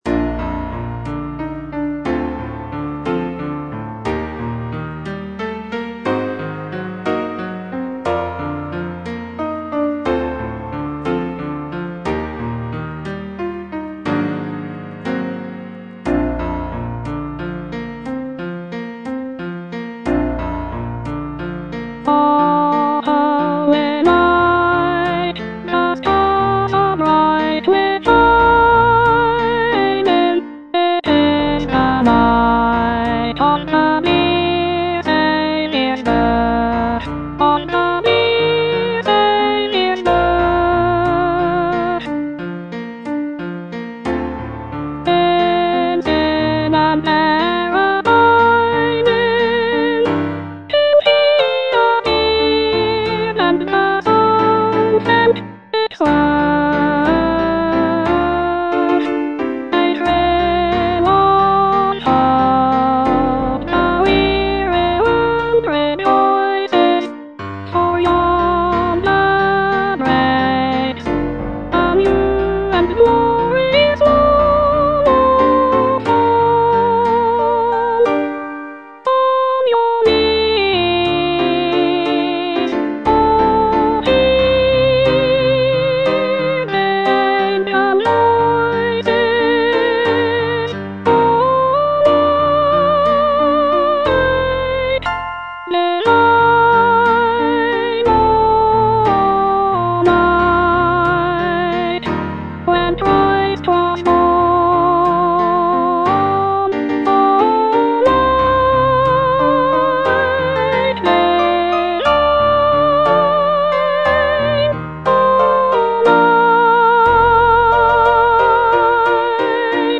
Soprano I (Voice with metronome)